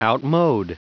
Prononciation du mot outmode en anglais (fichier audio)
Prononciation du mot : outmode